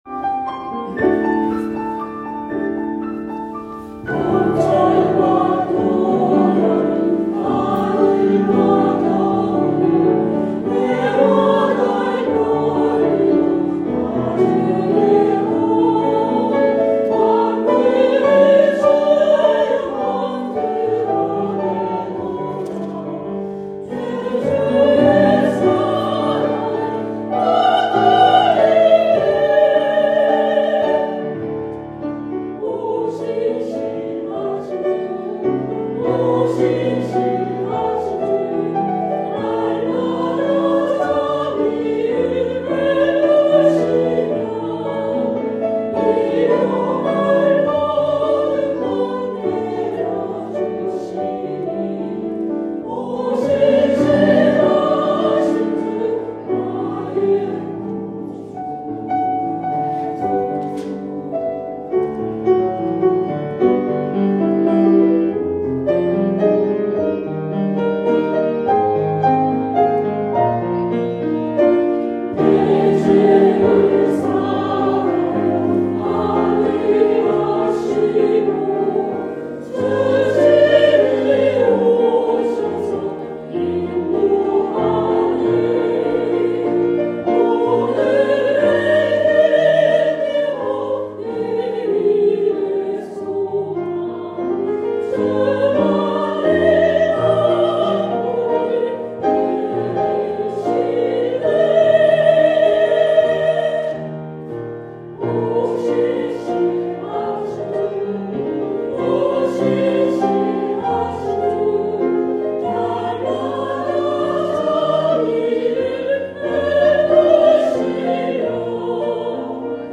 2024년 9월 8일 주일예배 쥬빌리